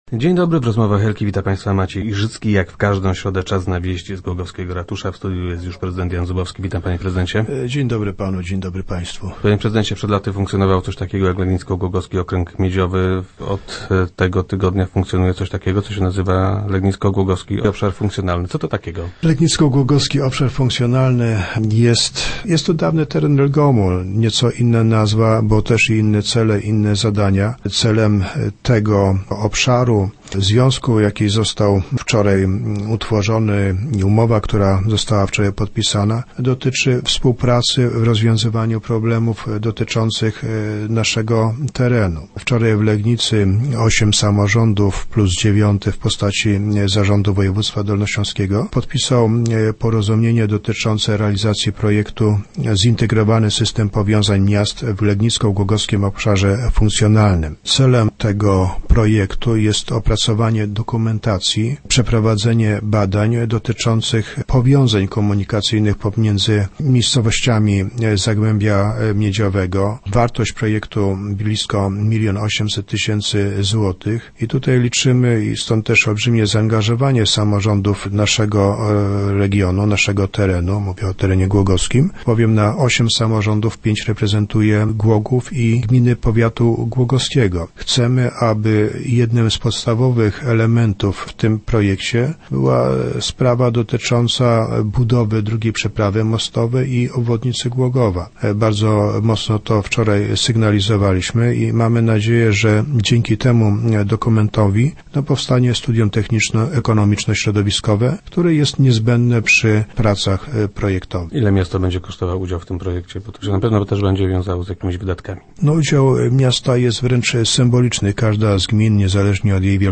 - Celem związku jest współpraca przy rozwiązywaniu problemów dotyczących naszego regionu - mówił na radiowej antenie prezydent Jan Zubowski, który był gościem Rozmów Elki.